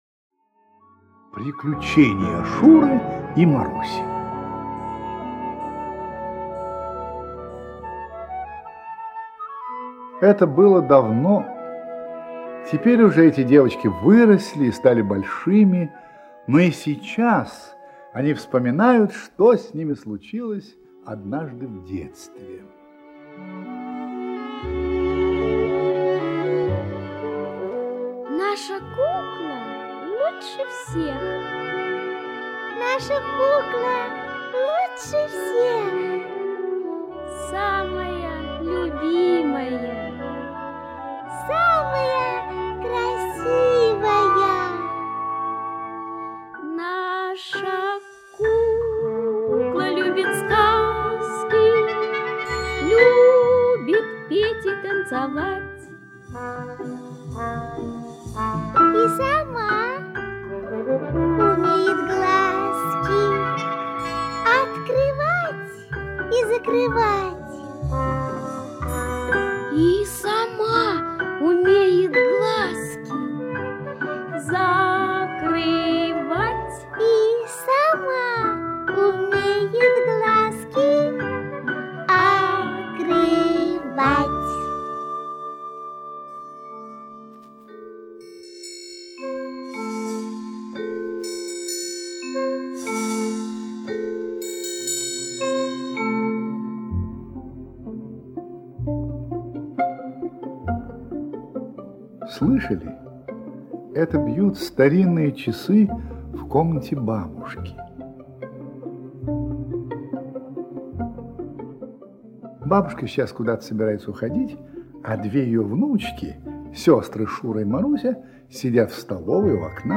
Приключения Шуры и Маруси - аудио рассказ Шварца Е. Поучительная история о двух сестренках, попавших в затруднительное положение.
Инсценировка